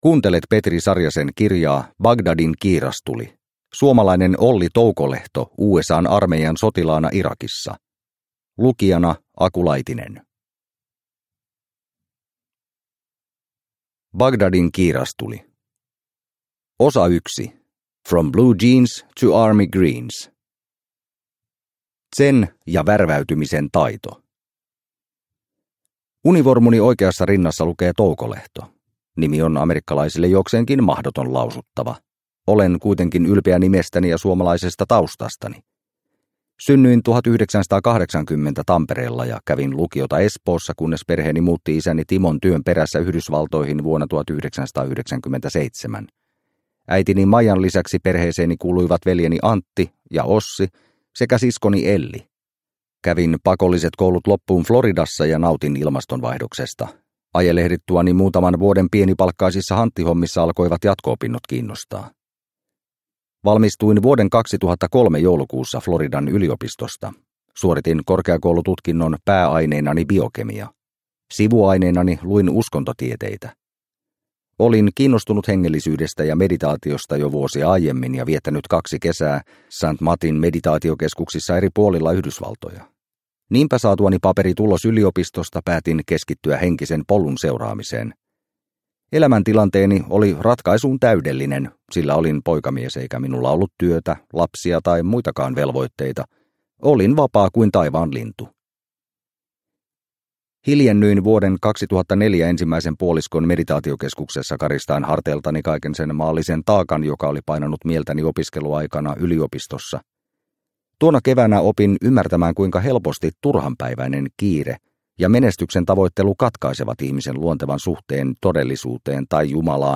Bagdadin kiirastuli – Ljudbok – Laddas ner